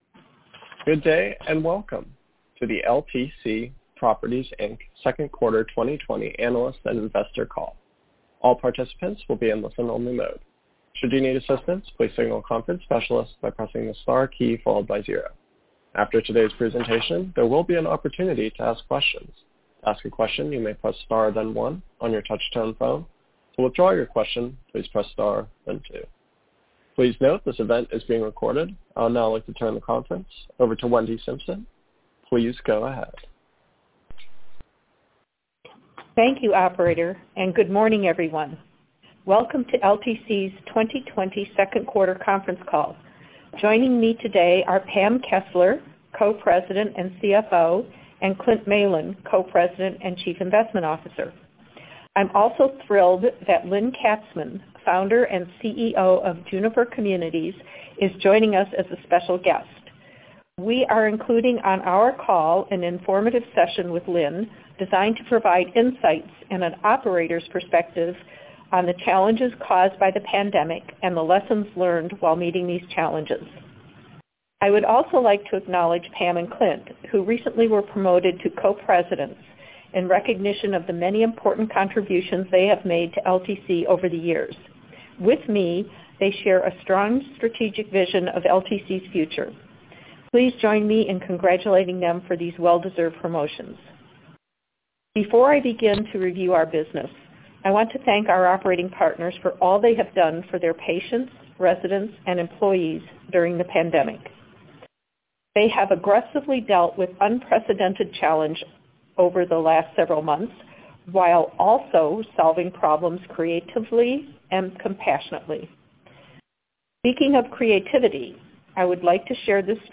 Audio-Replay-of-LTC-Properties-Inc-Q2-2020-Earnings-Call.mp3